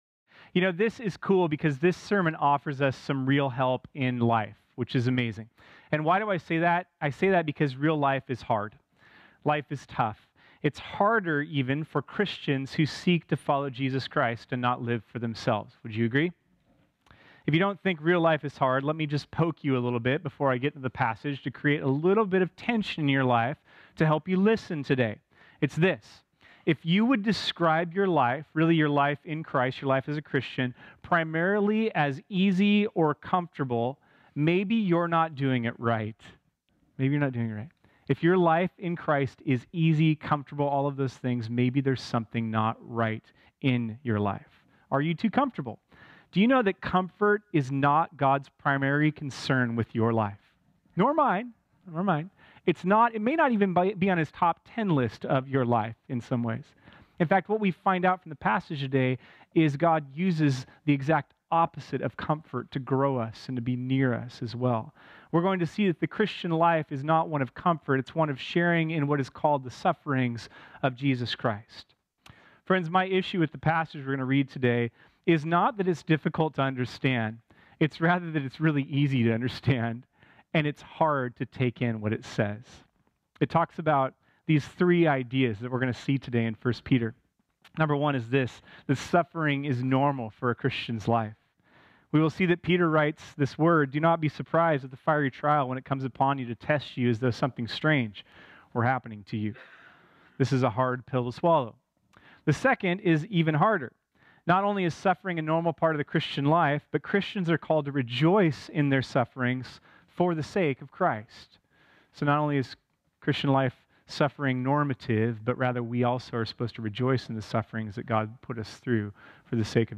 This sermon was originally preached on Sunday, April 15, 2018.